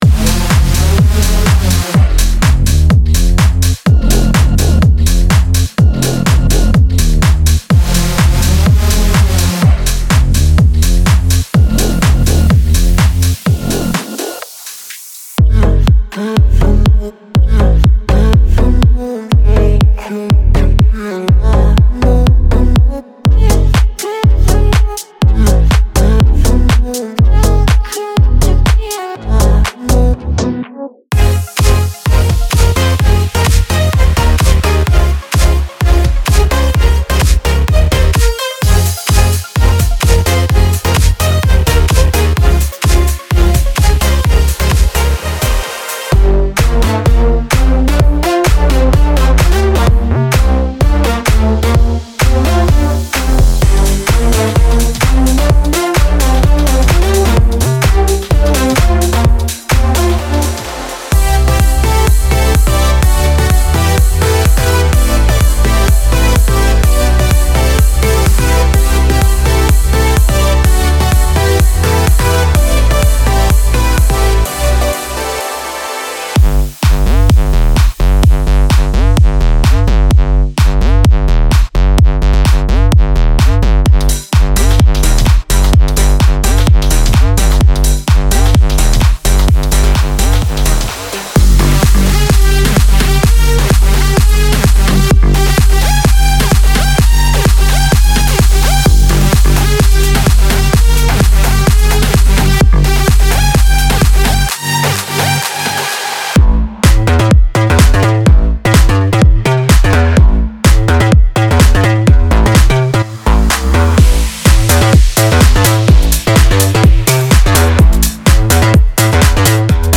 • 42 Basses
• 41 Leads
• 7 Pads
• 20 Plucks